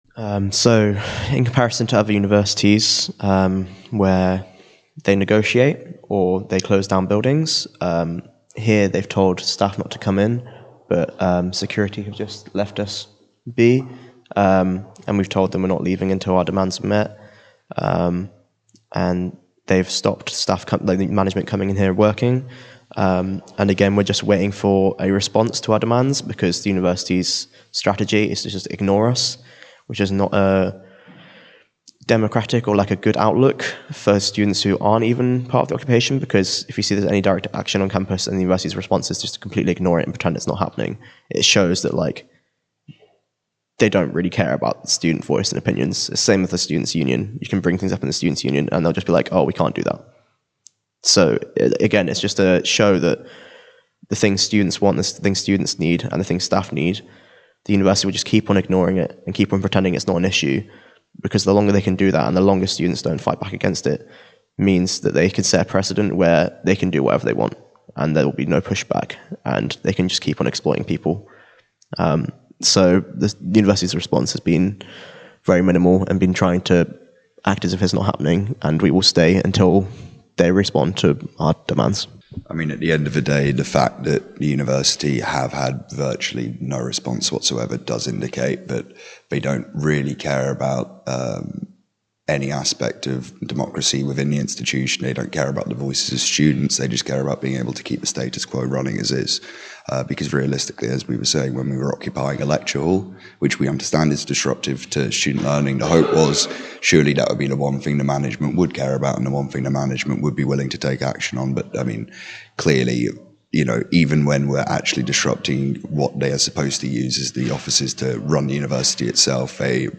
Q3: Trent Building Occupiers Interview